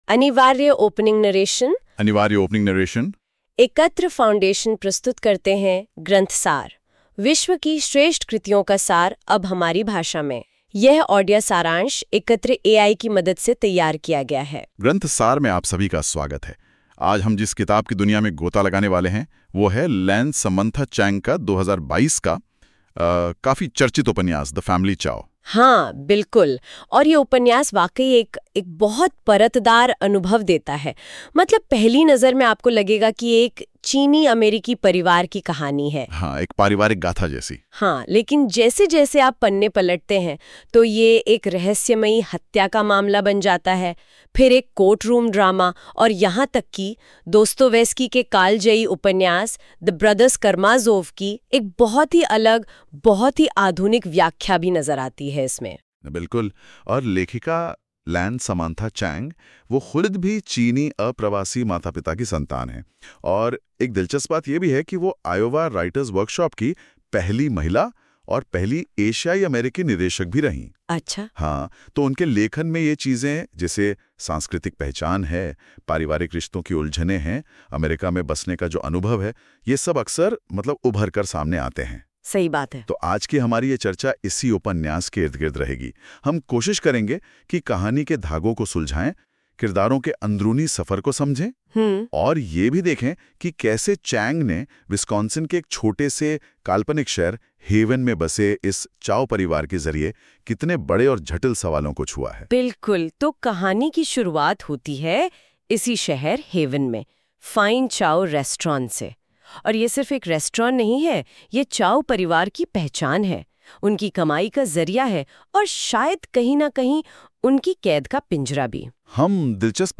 Ekatra audio summary – Hindi